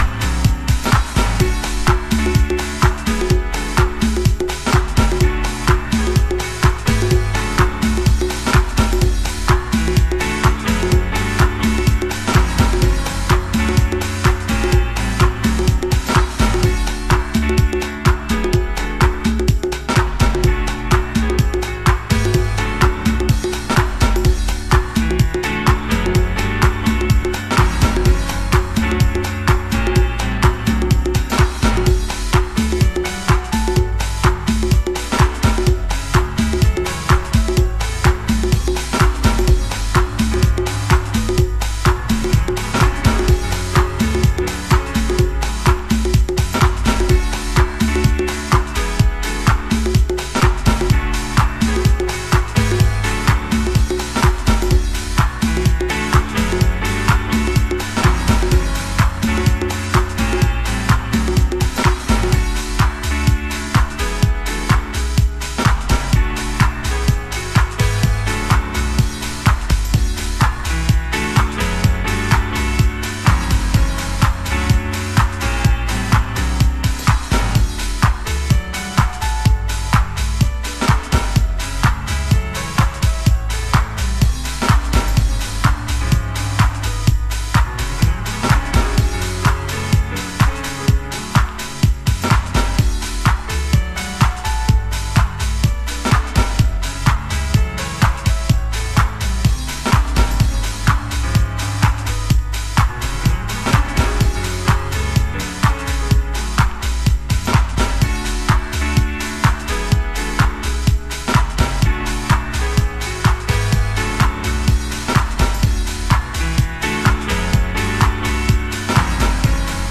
Inst. Mix
Early House / 90's Techno